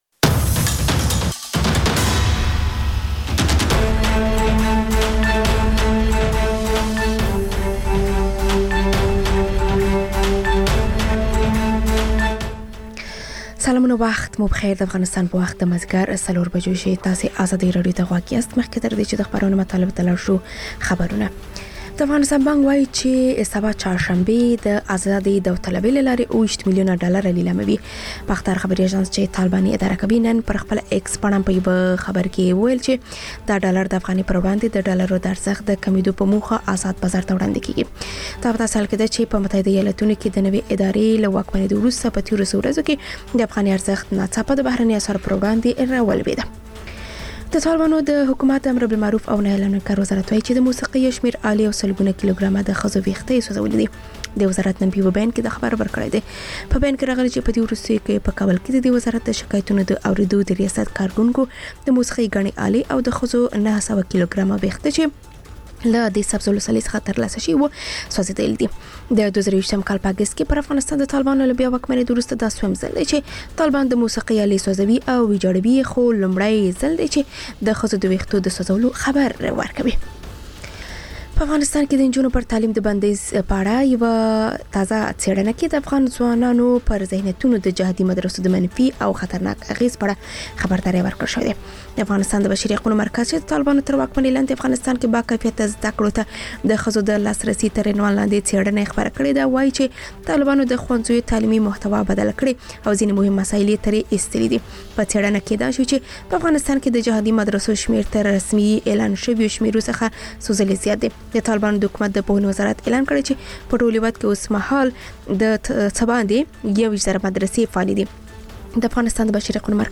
مازیګرنی خبري ساعت - P1 سټوډیو